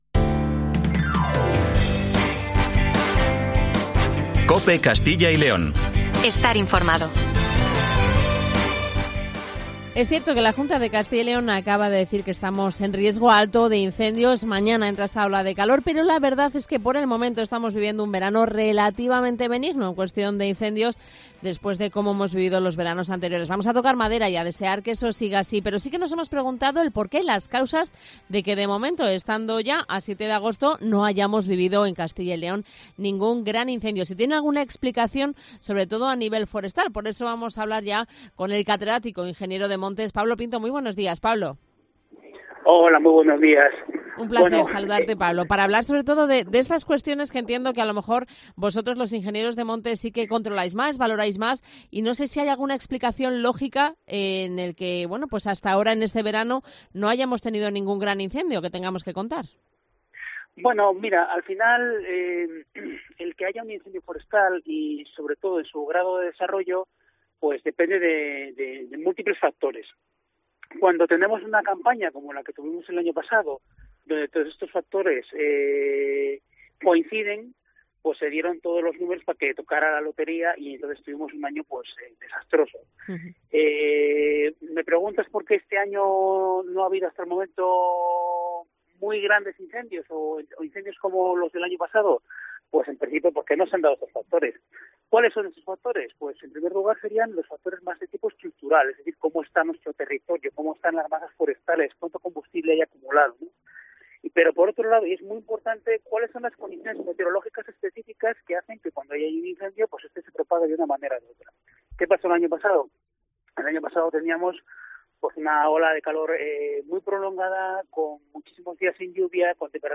catedrático e ingeniero de montes